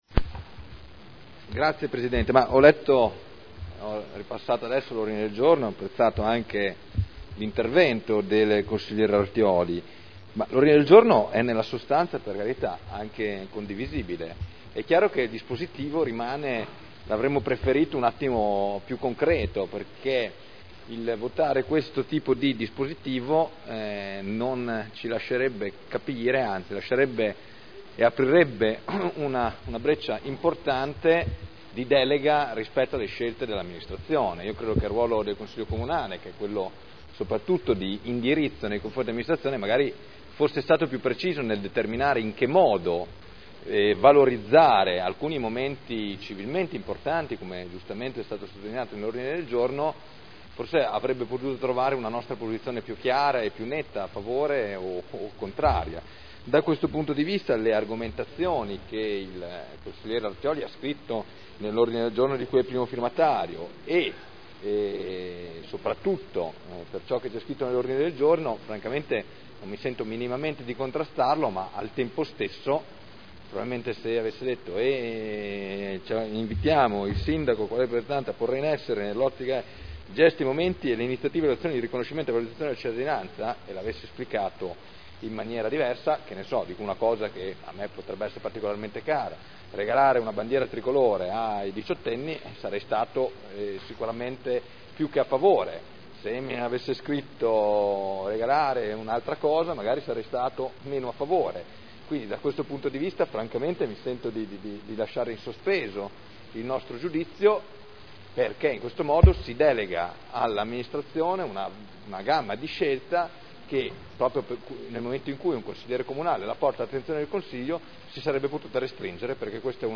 Seduta del 07/03/2011.